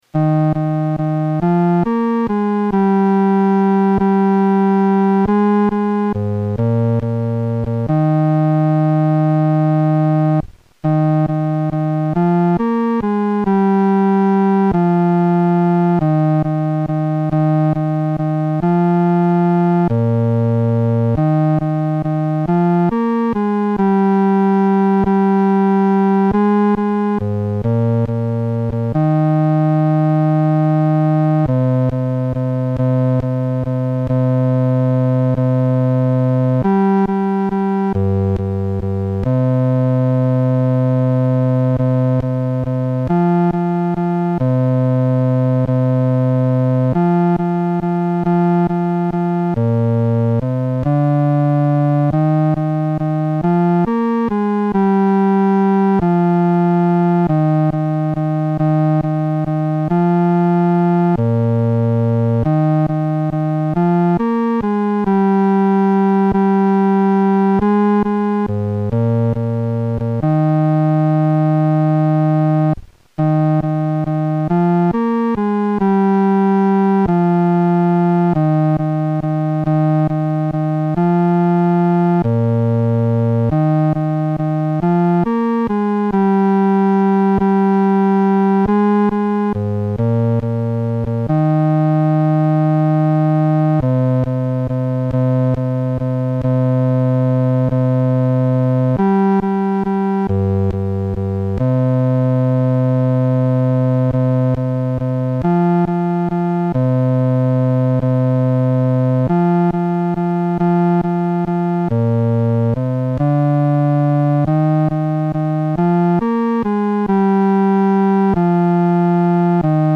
伴奏
男低
本首圣诗由网上圣诗班 (南京）录制
唱时速度可以流动一些，不宜拖沓。